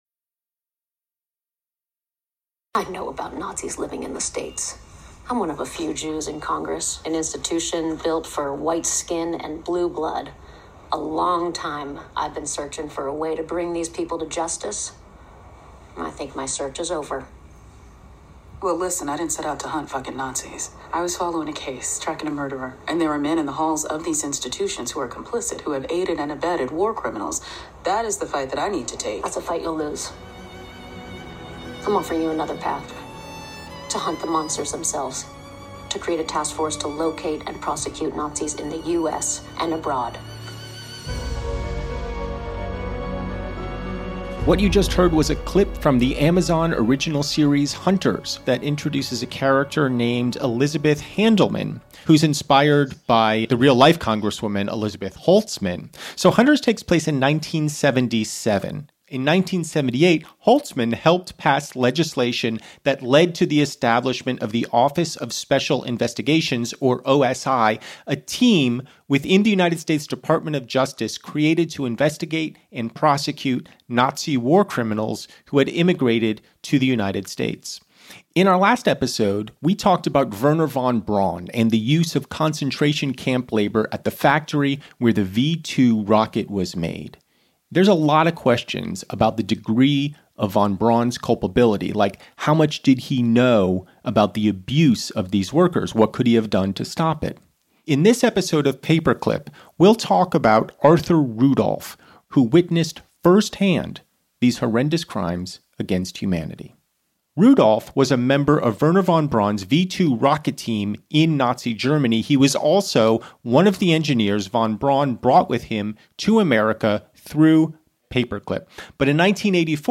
comedian Michael Ian Black
attorney Neal Sher, who ran the Rudolph investigation